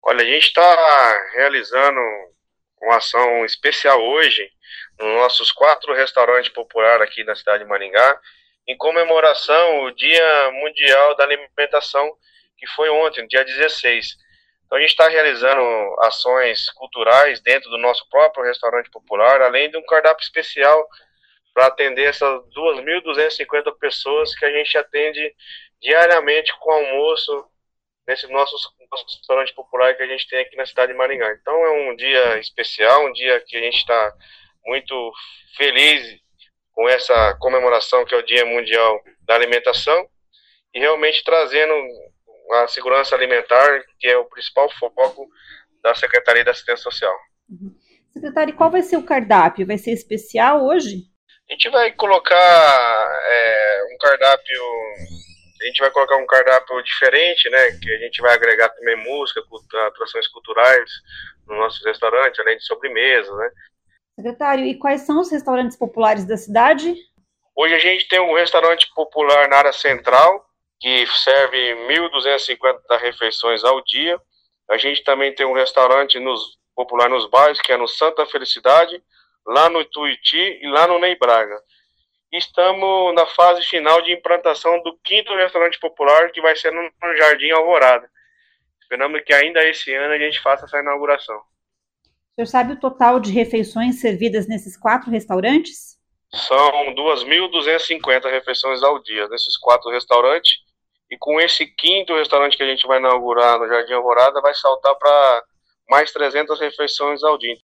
Os quatro restaurantes populares de Maringá servirão uma refeição especial nesta sexta-feira (17) em comemoração ao Dia Mundial da Alimentação, celebrado nessa quinta-feira, 16 de outubro. Ouça o que diz o secretário de Assistência Social de Maringá, Leandro Bravin.